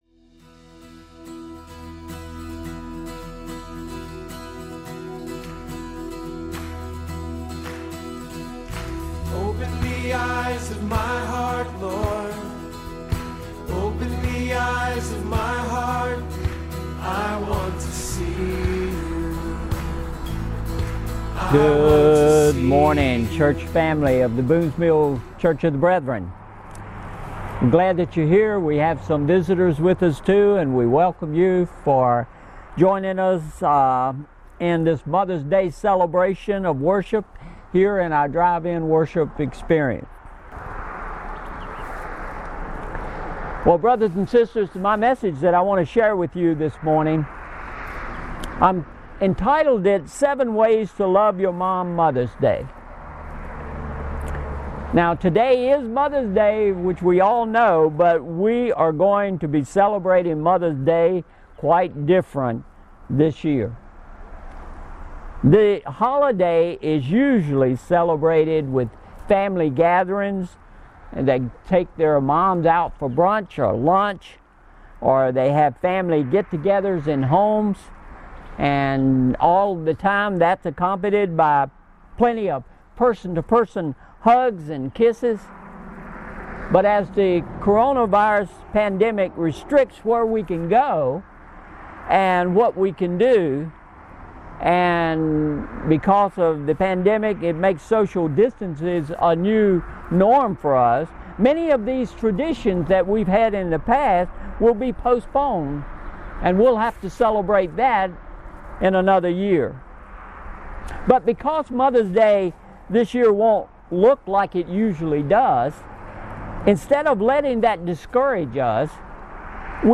Mother's Day Celebration